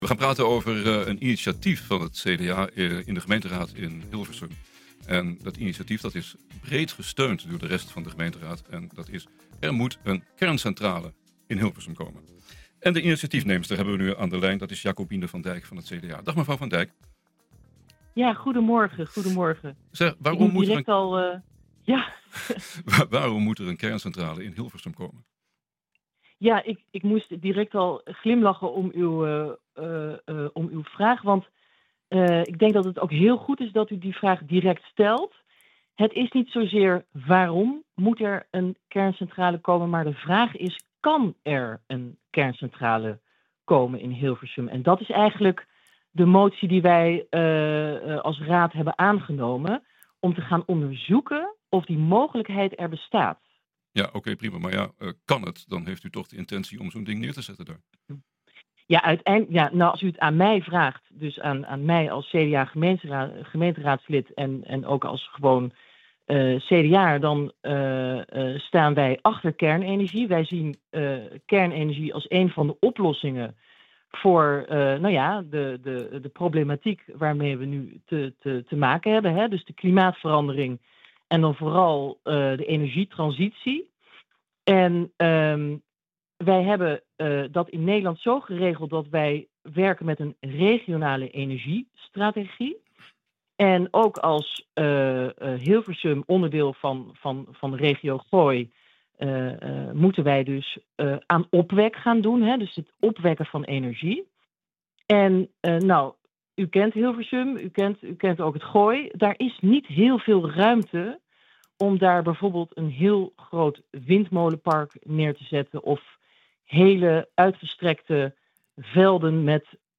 We gaan het over hebben met Jacobine van Dijk van CDA, initaitefnemer van deze motie.